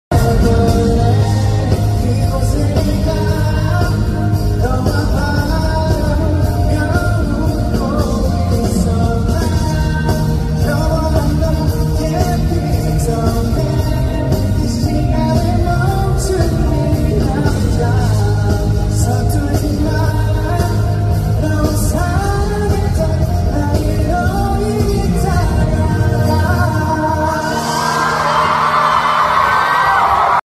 Asia Tour in Kuala Lumpur